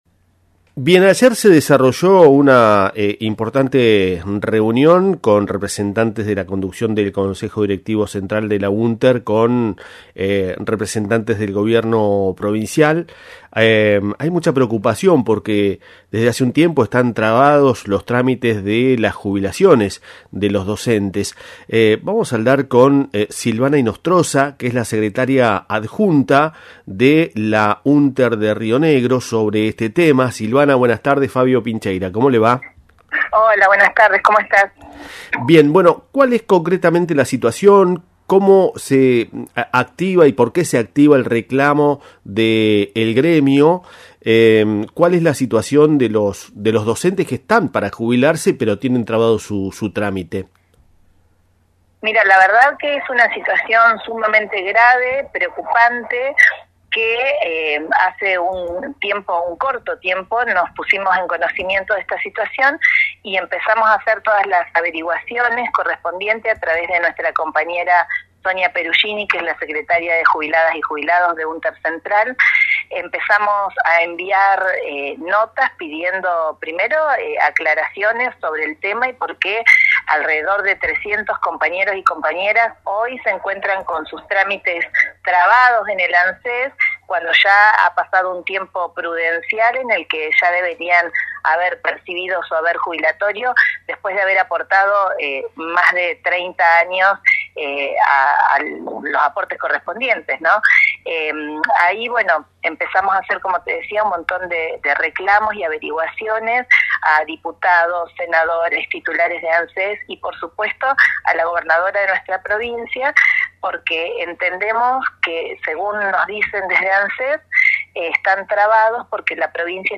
Secretaria Adjunta entrevista radiofónica